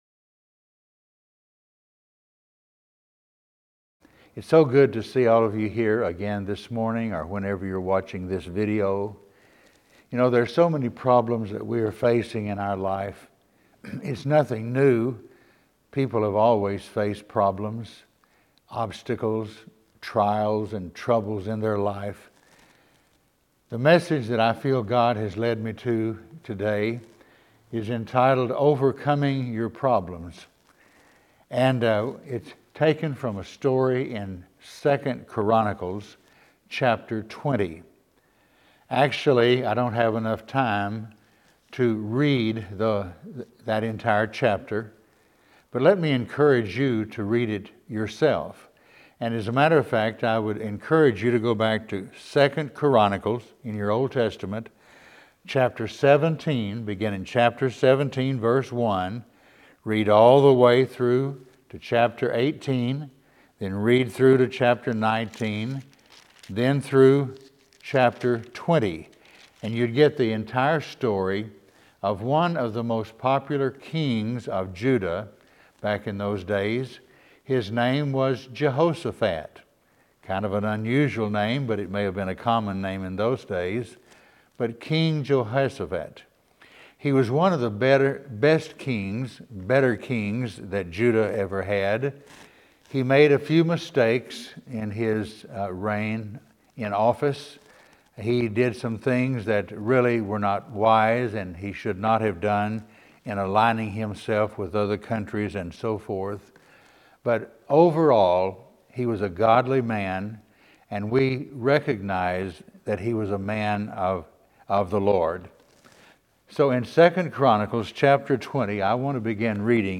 Preacher
Passage: 2 Chronicles 20 Service Type: Sunday Morning